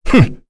Kain-Vox_Damage_03.wav